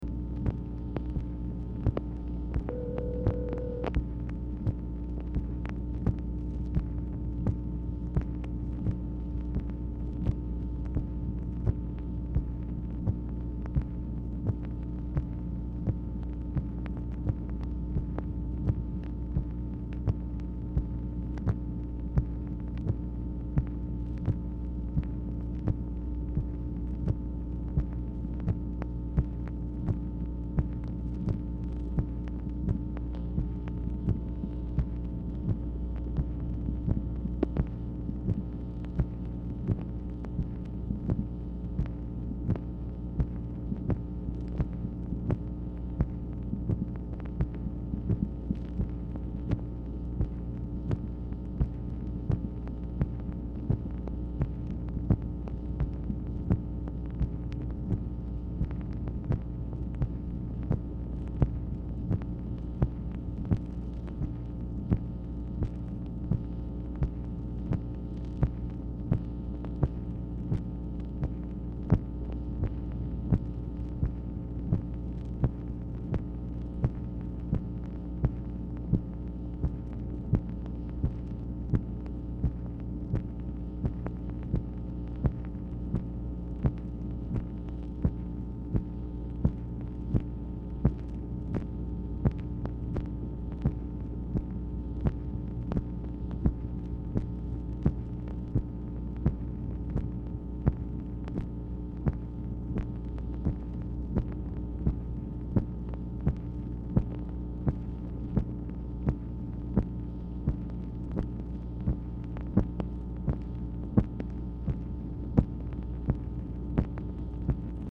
Telephone conversation # 10845, sound recording, MACHINE NOISE, 9/26/1966, time unknown | Discover LBJ
Format Dictation belt
Specific Item Type Telephone conversation